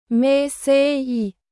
Yaziyi ses Yapma Api
Yaziyi ses yapmayı sağlayan api
yaziyi-ses-yapma